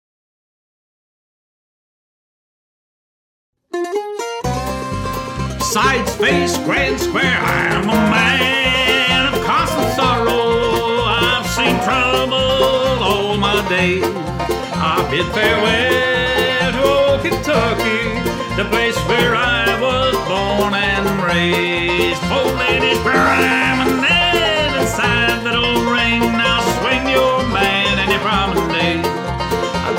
Singing Call
Voc